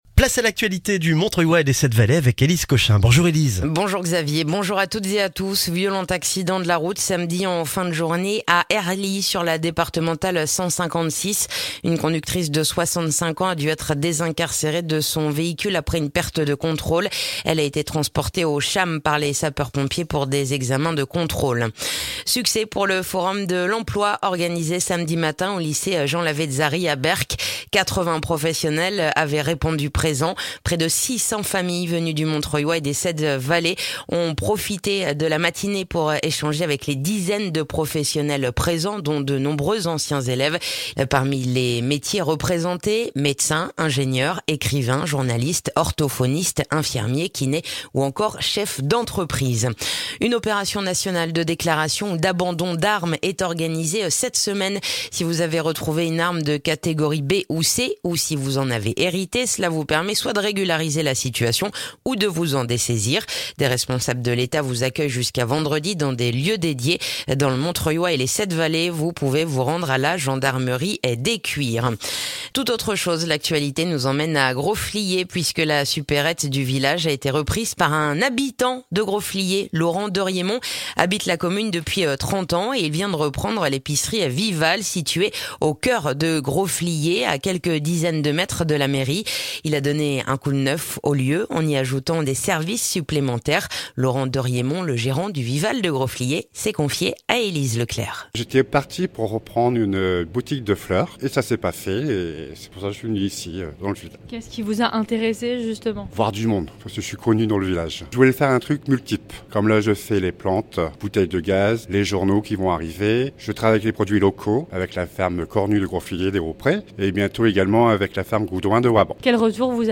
Le journal du lundi 28 novembre dans le montreuillois